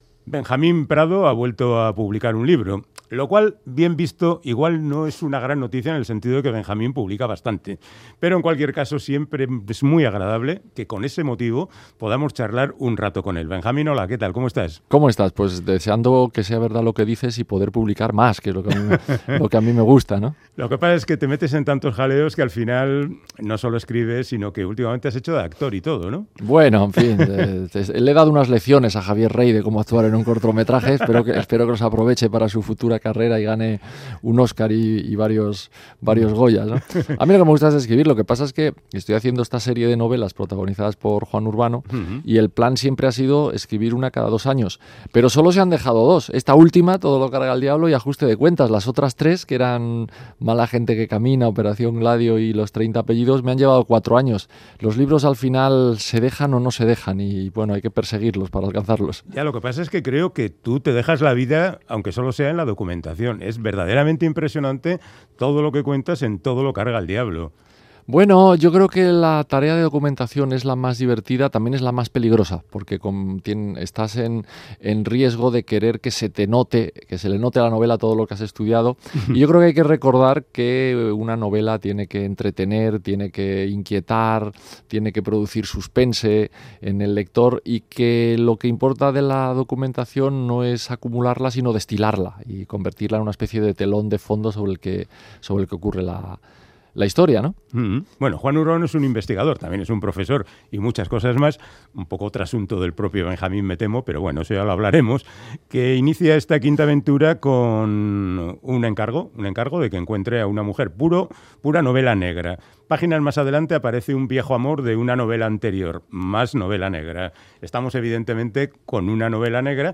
Audio: Charlamos con el madrileño Benjamín Prado sobre su novela "Todo lo carga el diablo", quinta entrega de las aventuras detectivescas del profesor Juan Urbano